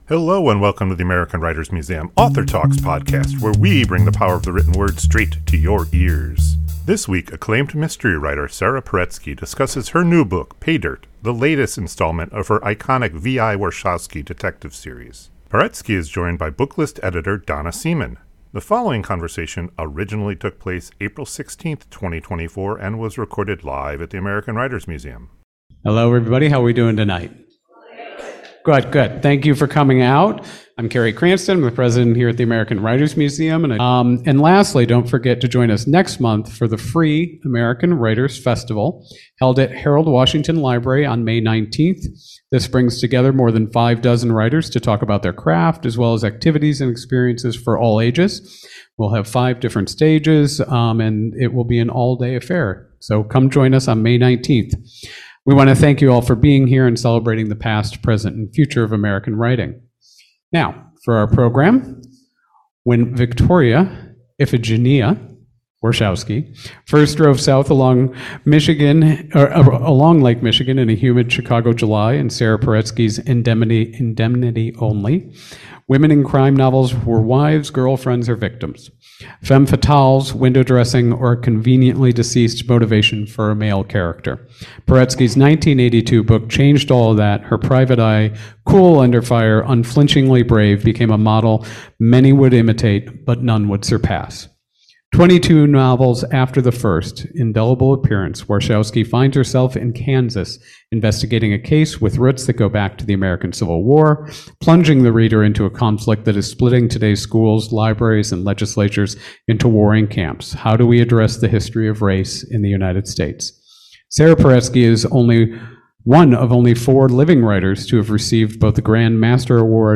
This conversation originally took place April 16, 2024 and was recorded live at the American Writers Museum.